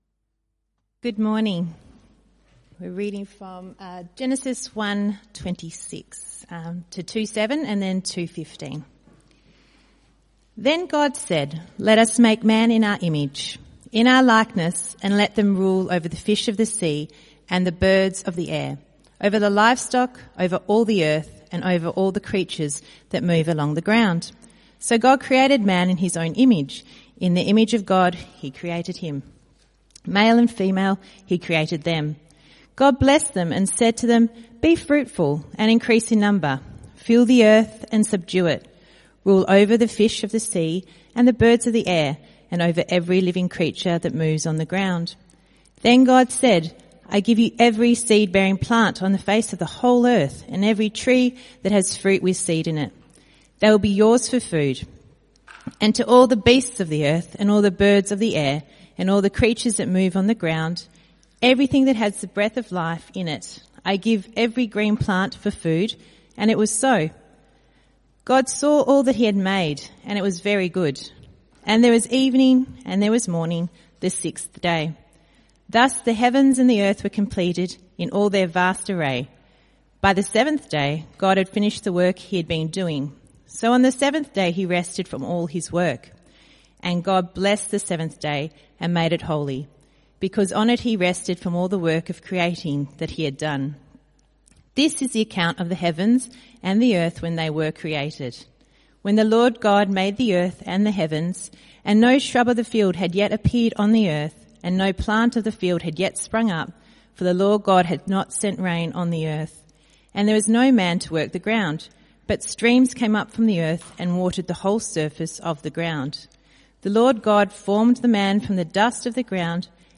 CBC Service: 10 August 2025 Series
Type: Sermons